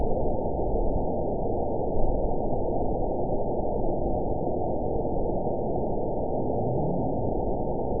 event 911196 date 02/16/22 time 11:14:04 GMT (3 years, 10 months ago) score 9.12 location TSS-AB01 detected by nrw target species NRW annotations +NRW Spectrogram: Frequency (kHz) vs. Time (s) audio not available .wav